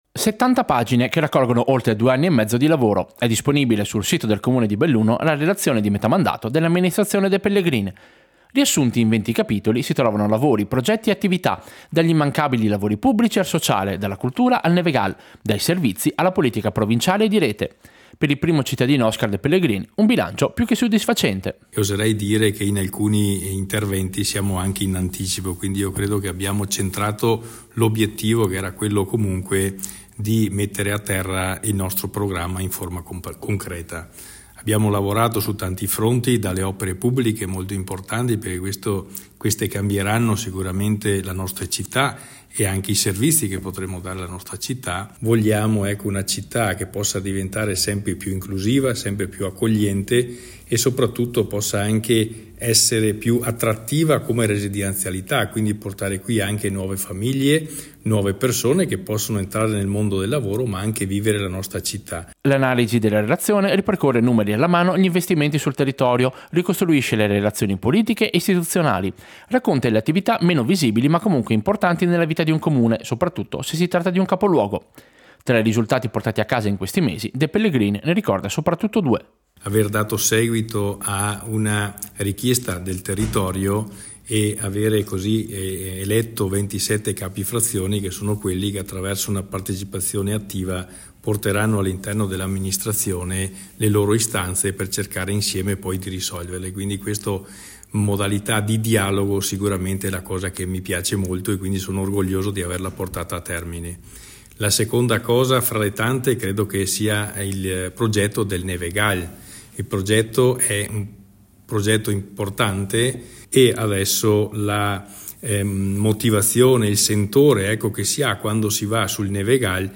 Servizio-Bilancio-meta-mandato-Belluno.mp3